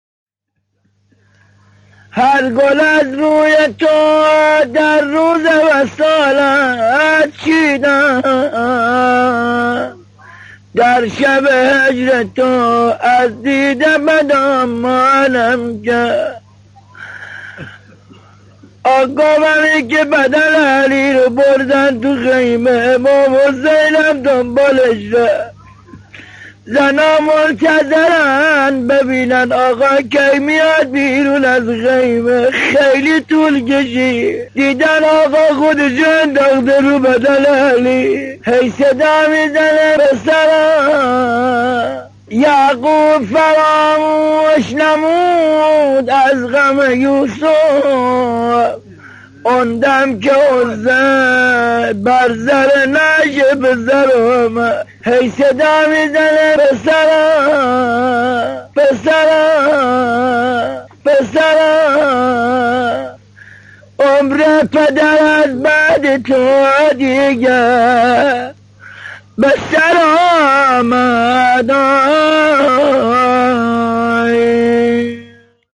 روضه حضرت علی اکبر علیه السلام